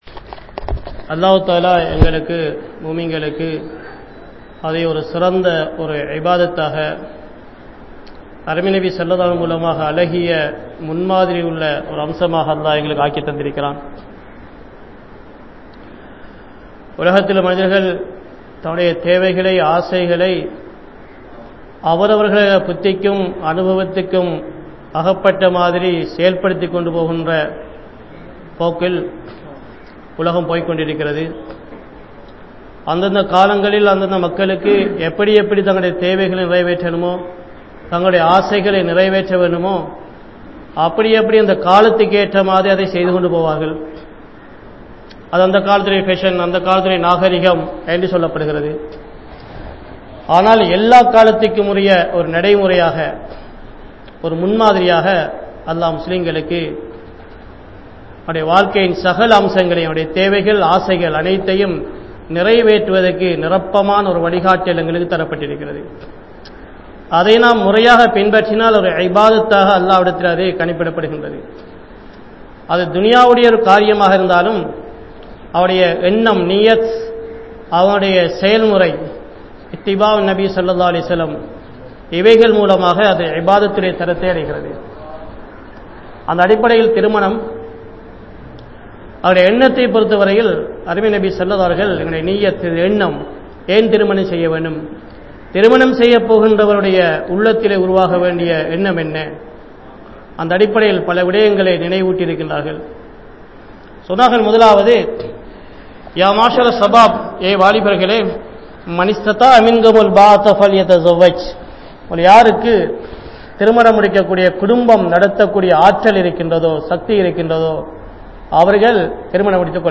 Paavangalai Thadukkum Nikkah (பாவங்களை தடுக்கும் திருமணம்) | Audio Bayans | All Ceylon Muslim Youth Community | Addalaichenai
Hussainiya Masjidh